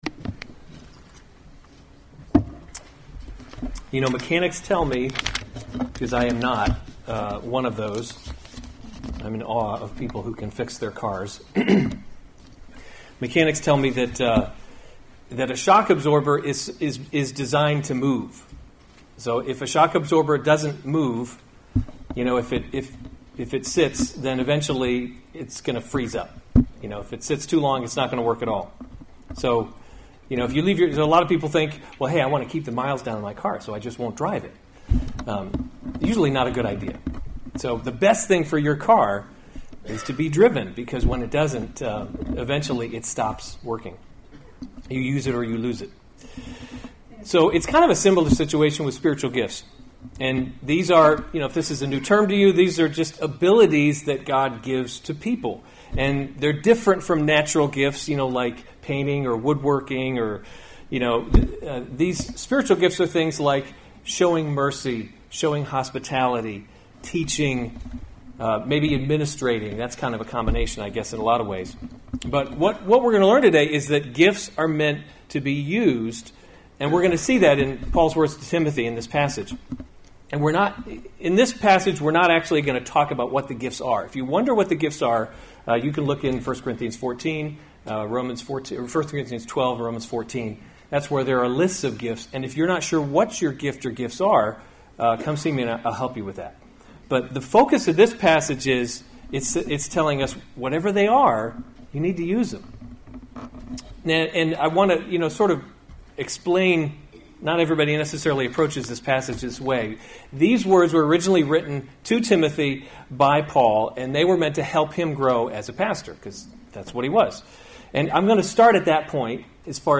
April 29, 2017 1 Timothy – Leading by Example series Weekly Sunday Service Save/Download this sermon 1 Timothy 4:11-16 Other sermons from 1 Timothy 11 Command and teach these things. 12 Let […]